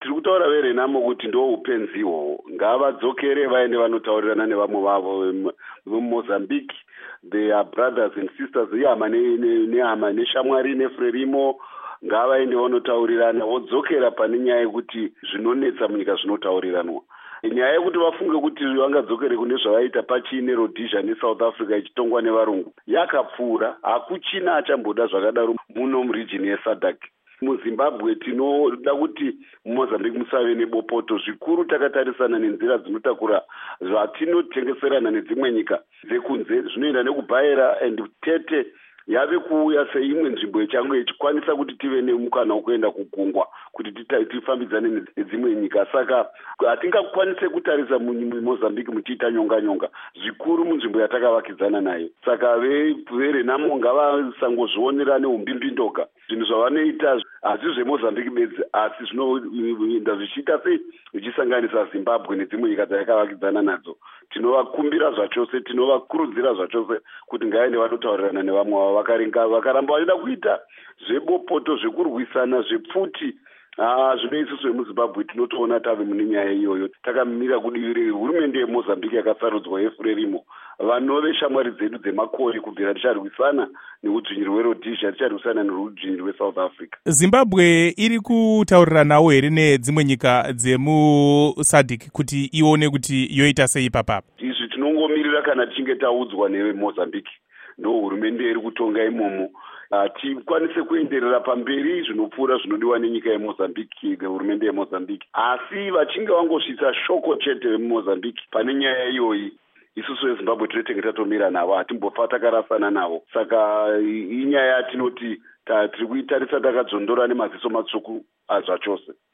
Embed share Hurukuro naVaChris Mutsvangwa by VOA Embed share The code has been copied to your clipboard.